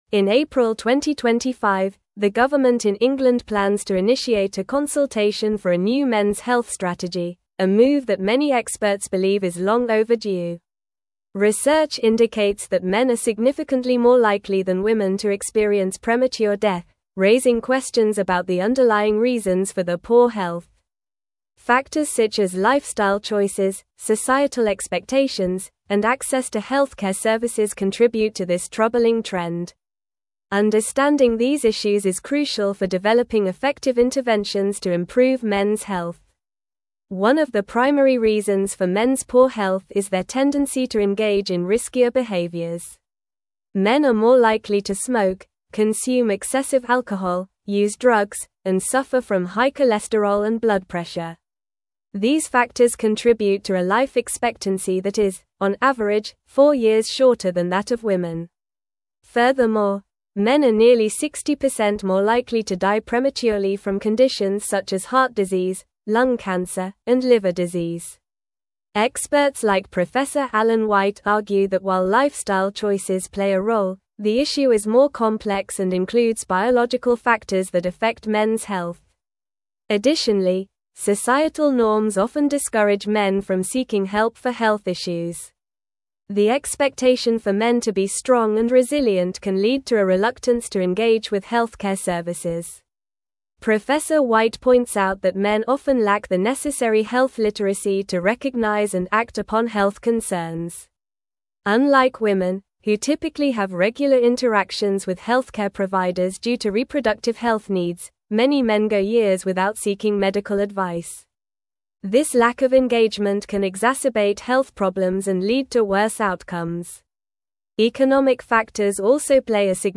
Normal
English-Newsroom-Advanced-NORMAL-Reading-UK-Government-Launches-Consultation-for-Mens-Health-Strategy.mp3